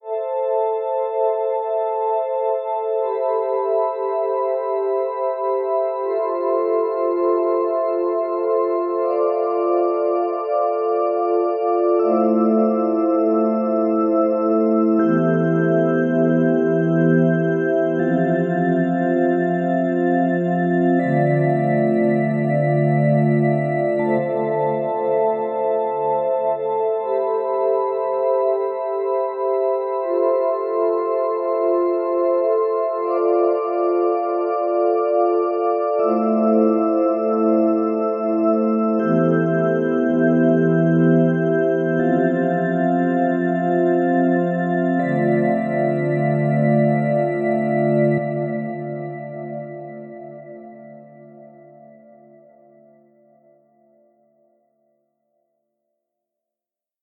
Calm Synthesizer, B
80bpm 80BPM ambiance Ambiance ambience Ambience calm Calm sound effect free sound royalty free Memes